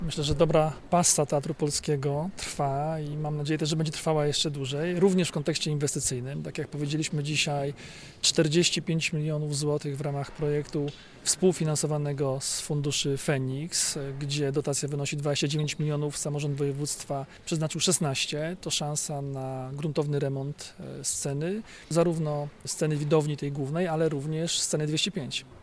– To szansa na gruntowny remont – mówi Jarosław Rabczenko, członek Zarządu Województwa Dolnośląskiego.